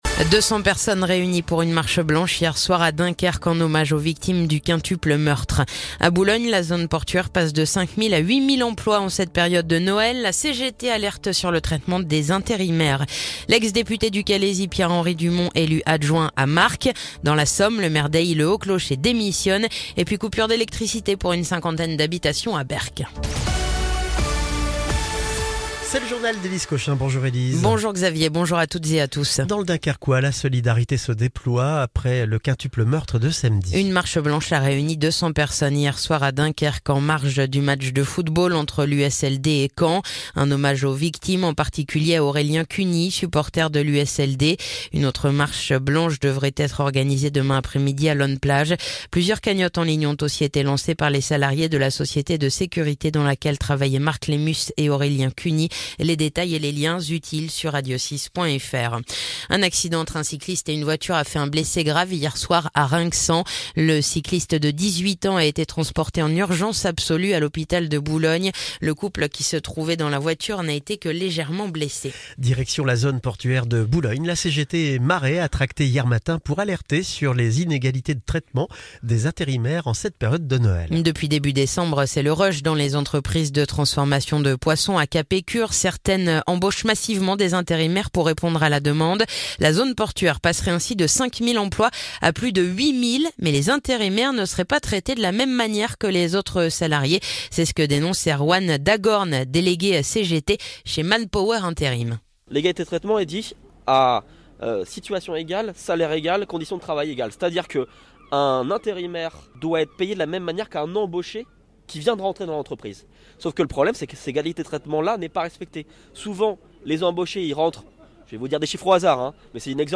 Le journal du mardi 17 décembre